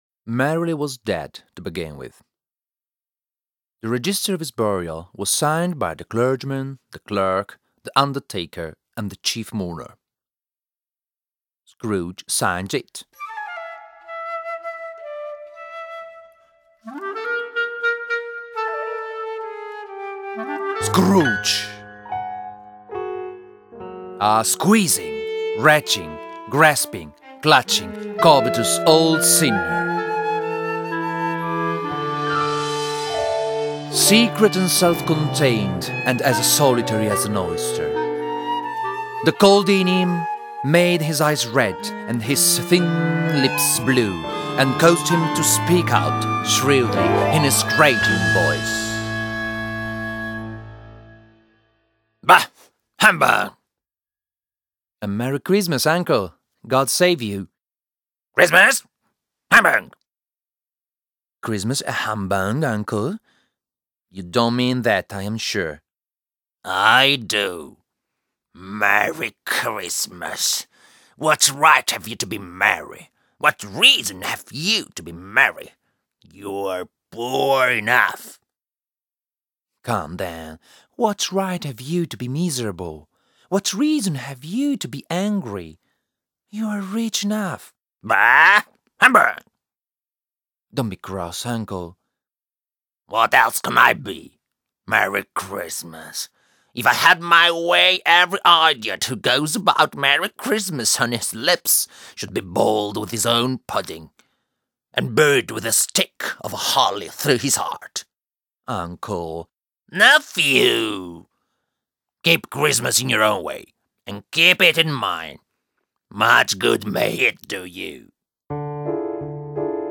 SEI OPERE PER VOCI E ENSEMBLE